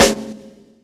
Snares
GFS_SNR.wav